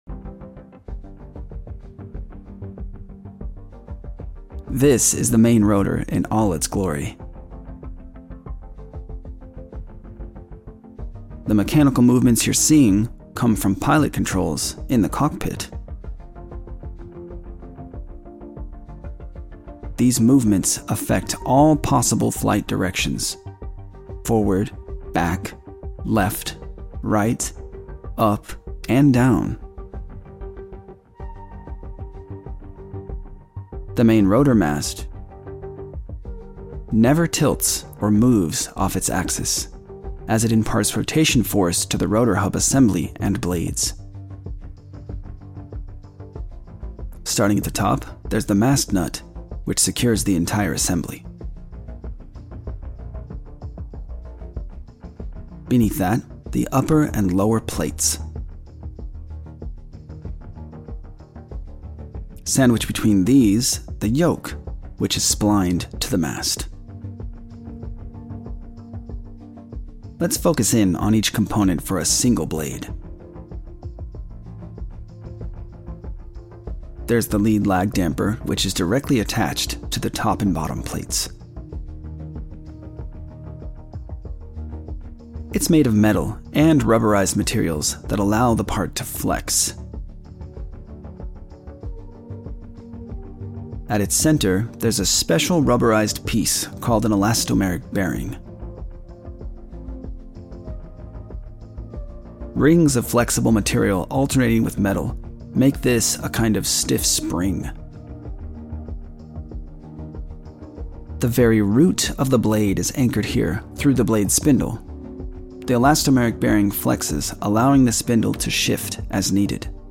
08 rotor sound effects free download